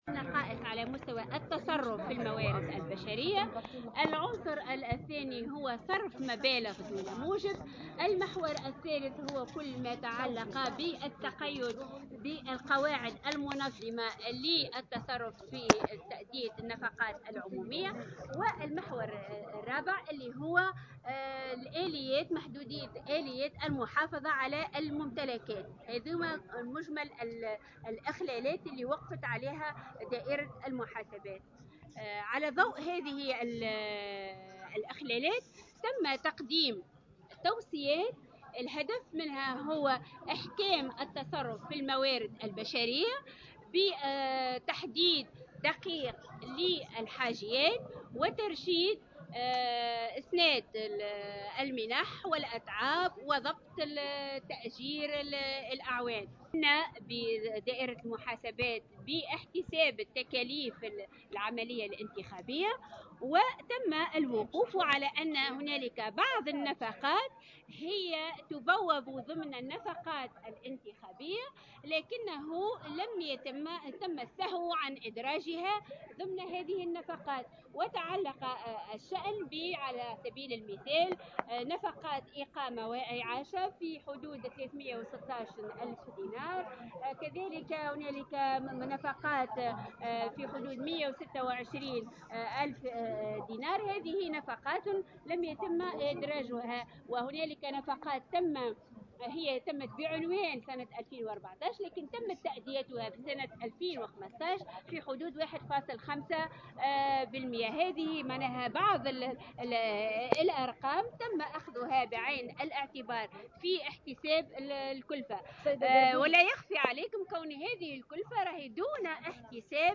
La Cour des comptes a dévoilé jeudi, lors d'une conférence de presse tenue à Tunis, son compte rendu portant sur le contrôle de la gestion financière de l'Instance Supérieure Indépendante pour les Élections (ISIE).